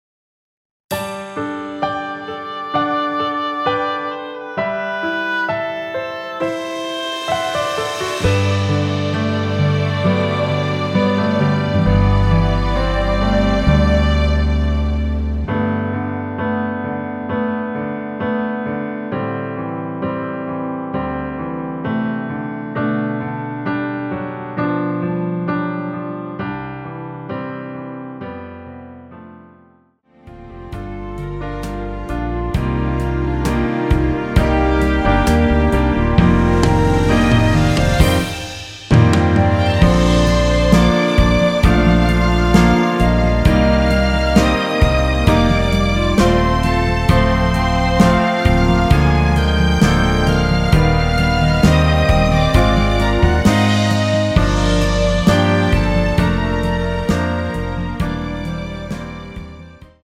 노래방에서 음정올림 내림 누른 숫자와 같습니다.
노래방에서 노래를 부르실때 노래 부분에 가이드 멜로디가 따라 나와서
앞부분30초, 뒷부분30초씩 편집해서 올려 드리고 있습니다.
중간에 음이 끈어지고 다시 나오는 이유는